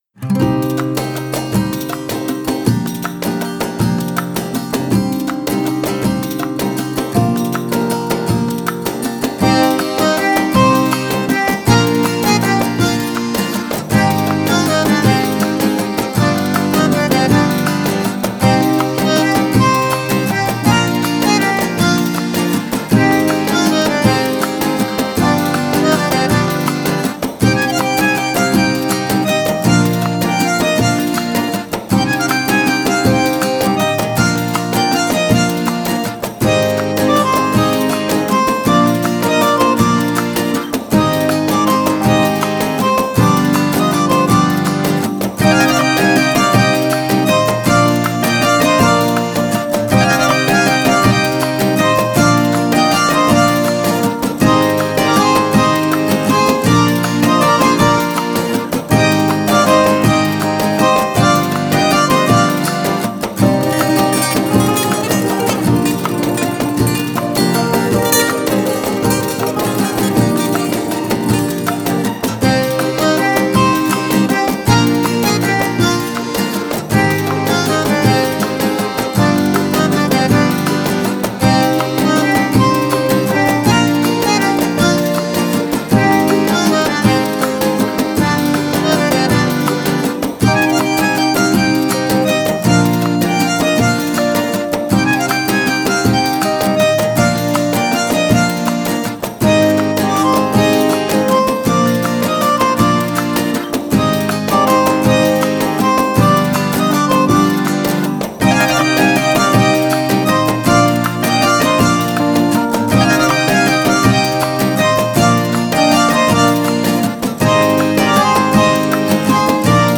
mutlu huzurlu rahatlatıcı fon müziği.